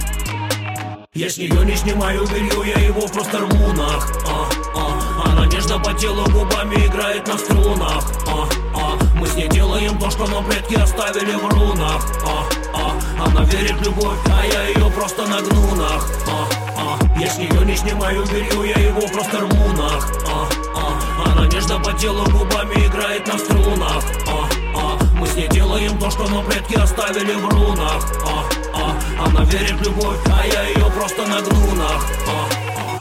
• Качество: 128, Stereo
Хип-хоп